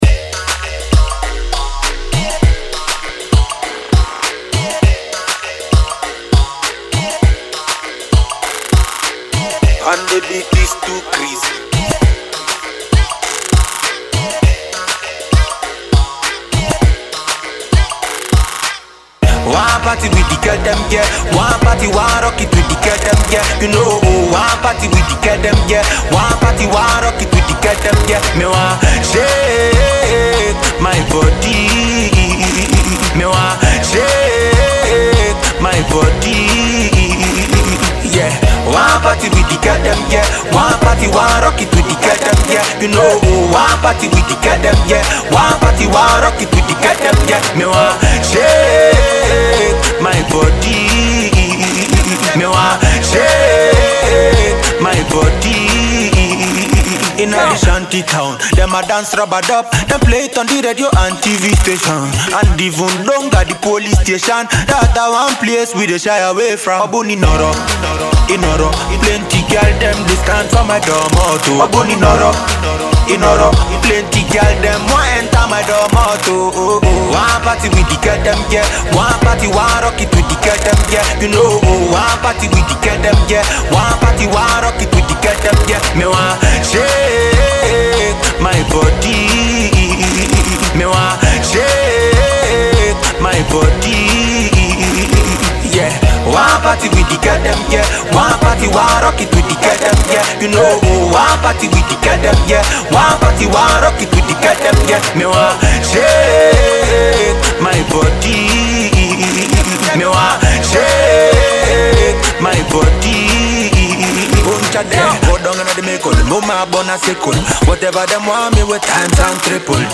infectious track